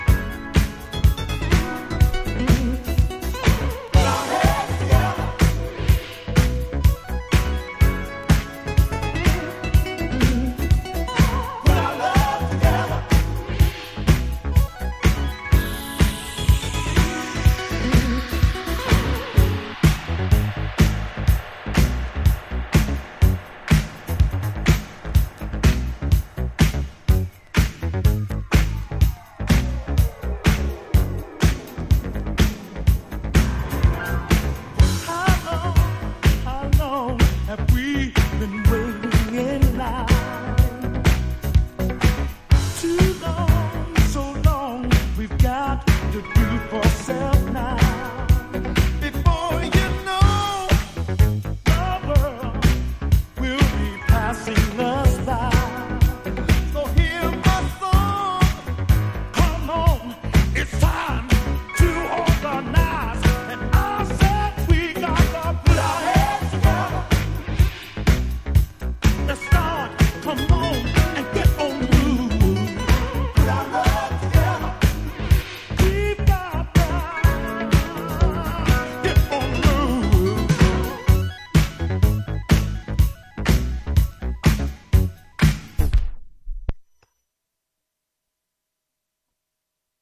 # DISCO# NU-DISCO / RE-EDIT